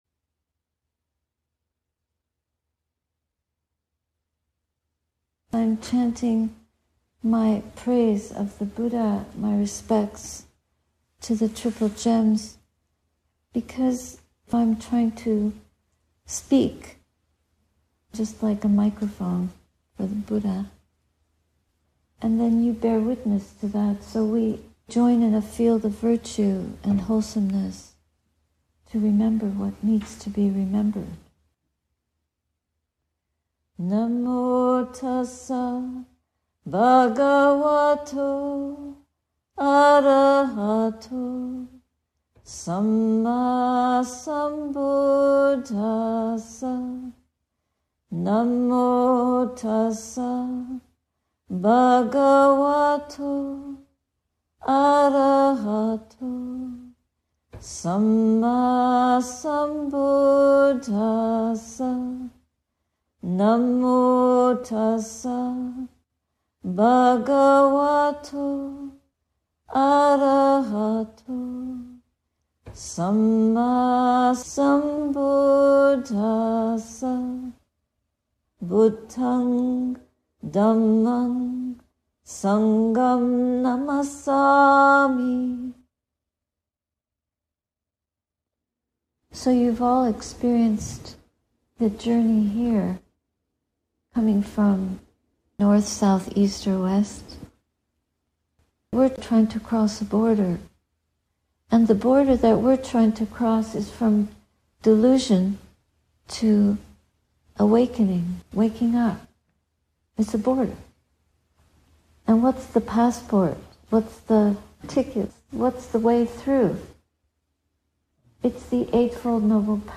Yes, we’re in self-drive – selfless – crossing to the far shore, Nibbana. SIMT annual retreat, Chapin Mill, Rochester, May 28, 2023